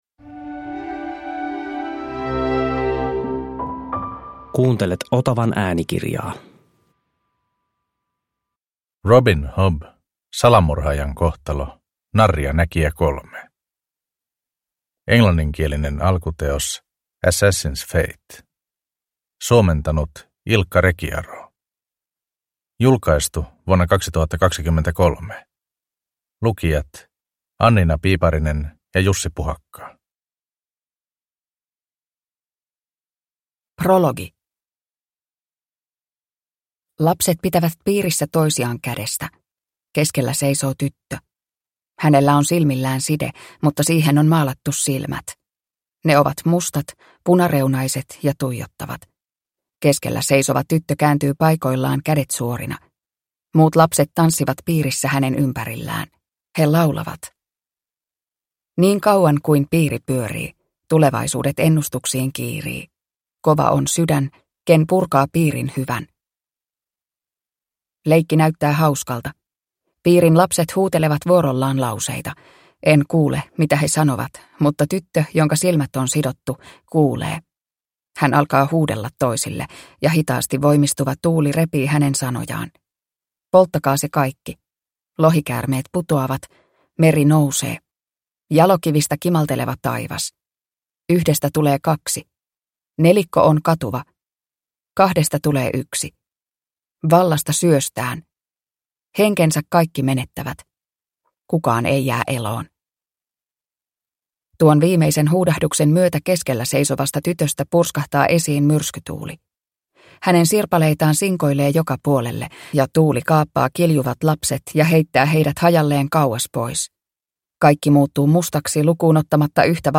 Salamurhaajan kohtalo – Ljudbok – Laddas ner